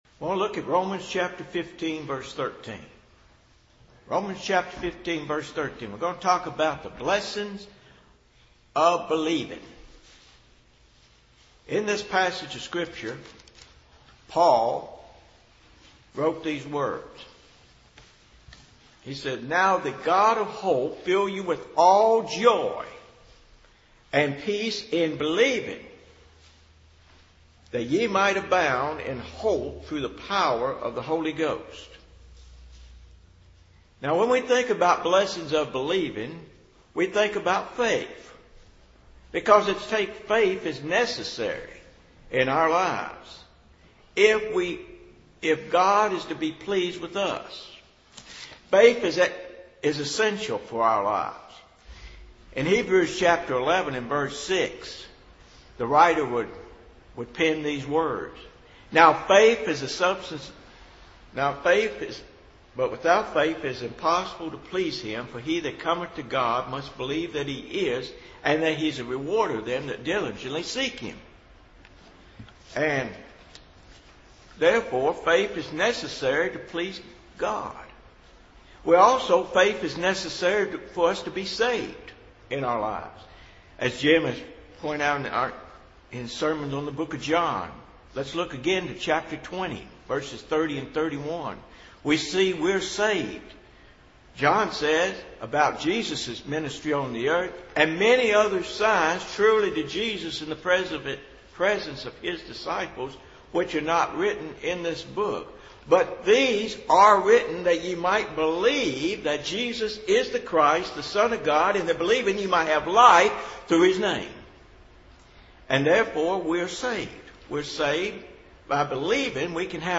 <Back to Sermon Audio main page